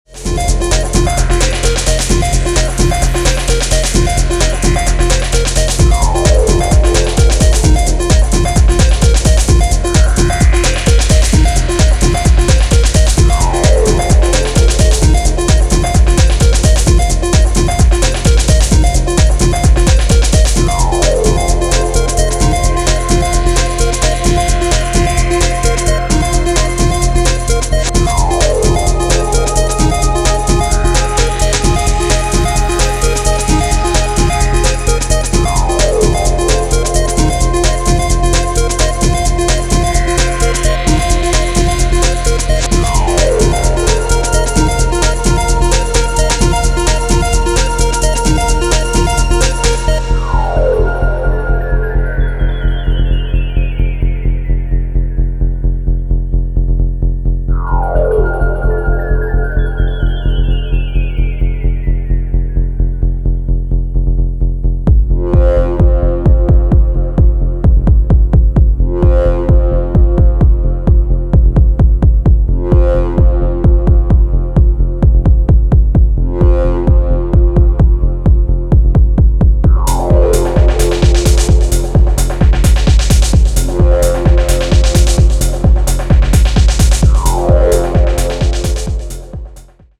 野外レイヴのクライマックスを想定していそうな、かなり気合の入った内容です。